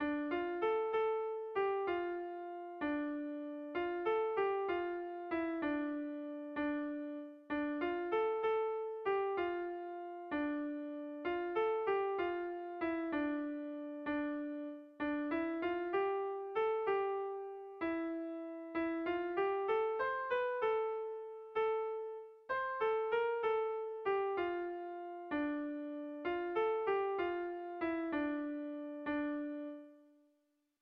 Erlijiozkoa
Zortziko txikia (hg) / Lau puntuko txikia (ip)
AABA2